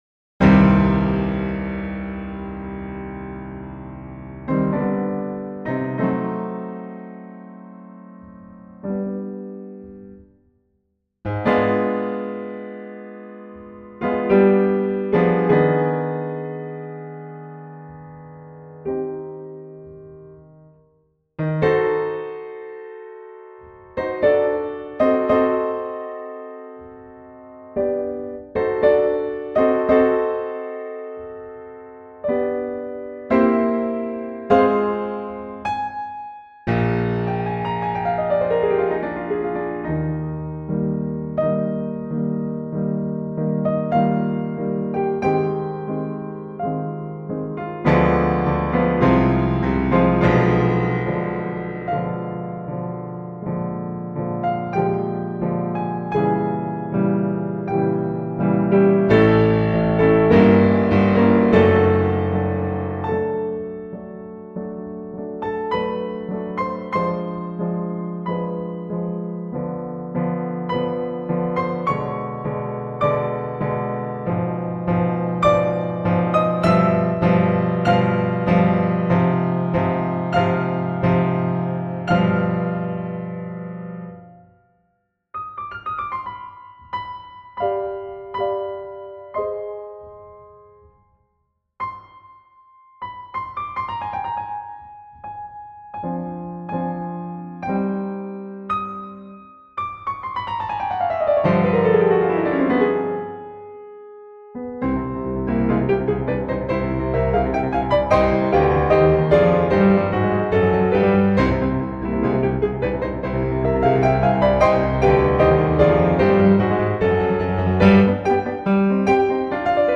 solo piano
Style: Classical